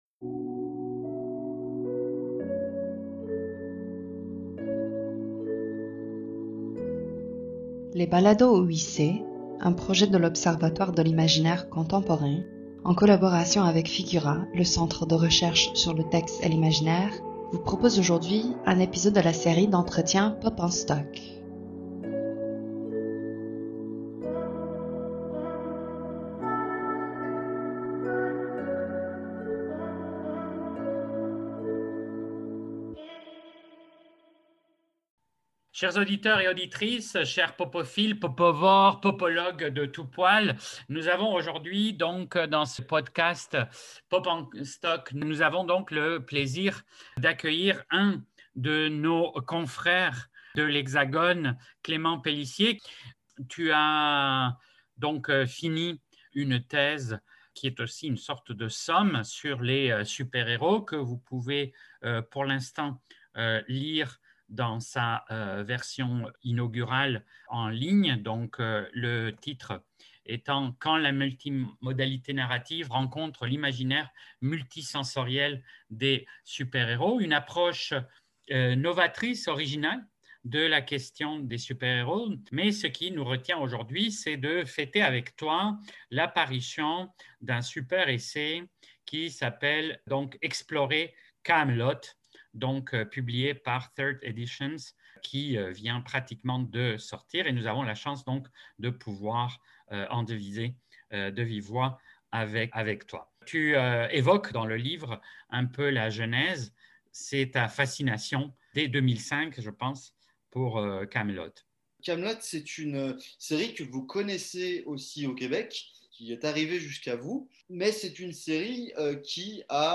Dans le cadre de cet entretien